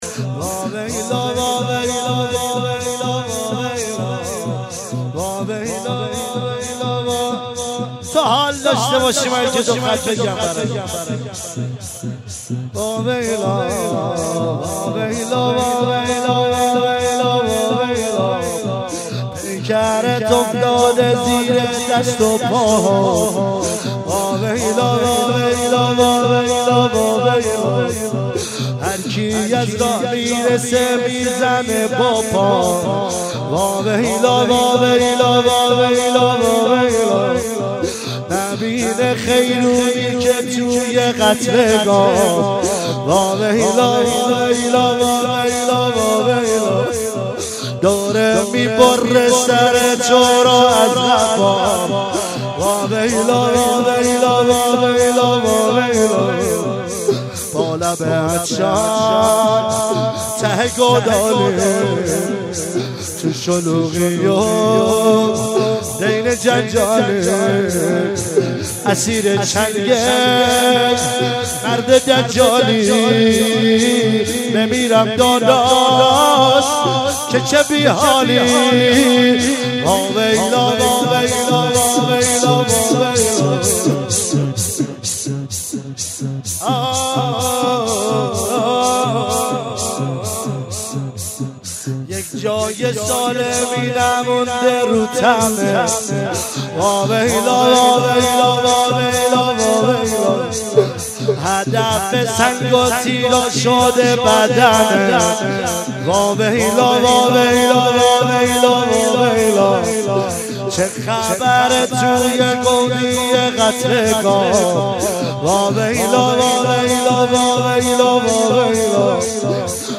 مراسم فاطمیه اول ۹۶
شور